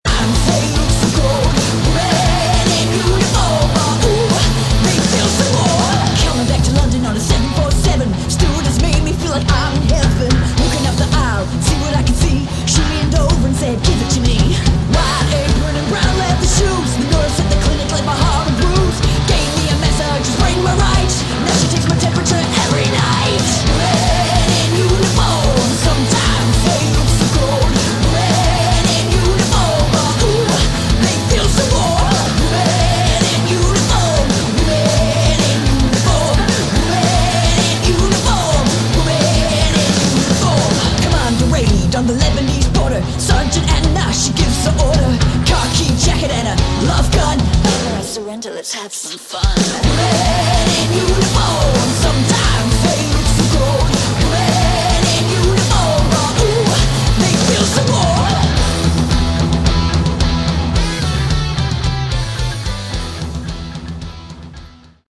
Category: Hard Rock/Punk Rock
vocals
drums
bass
guitar